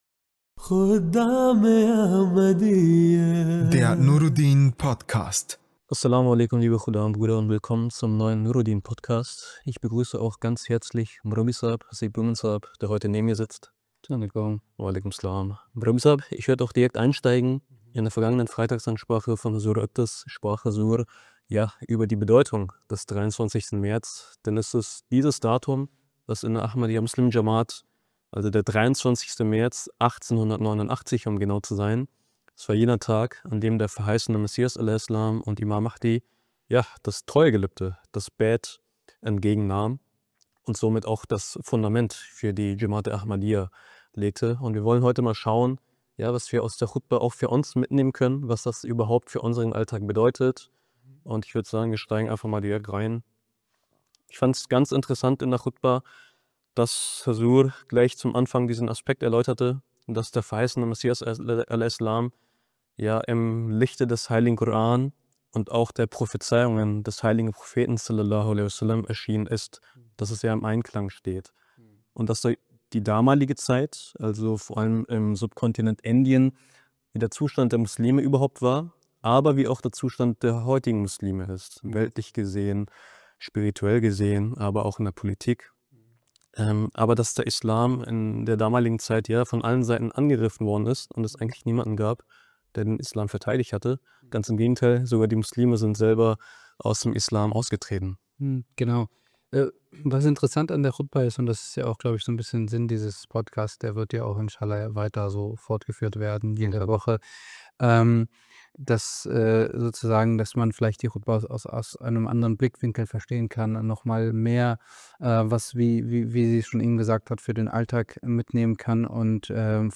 In einem offenen Gespräch tauschen sie ihre Gedanken über die Khutba aus und ziehen praktische Lehren für den Alltag.